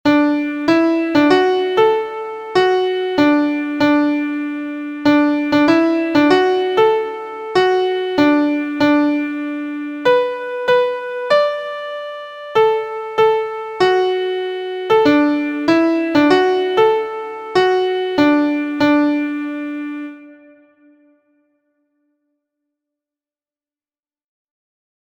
• Origin: USA – Traditional
• Key: D Major
• Time: 4/4
• Form: staves: AaBA – song: AB verse/refrain
• Pitches: intermediate: Do Re Mi So La Do